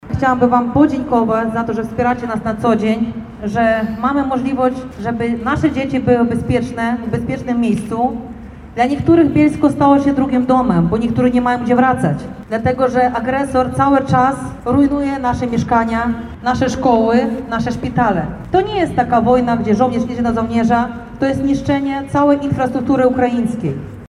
Bielszczanie spotkali się na pl. Chrobrego, aby pomodlić się za pokój w zaatakowanym kraju.